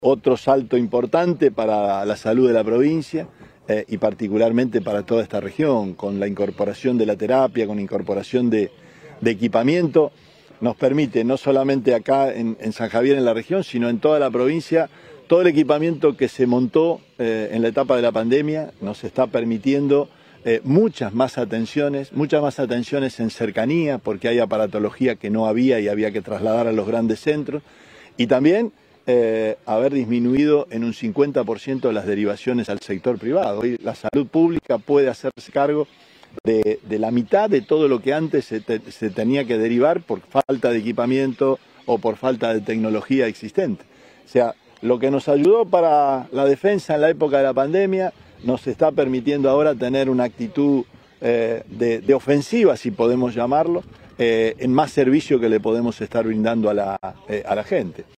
Declaraciones Perotti recorrida por el hospital San Javier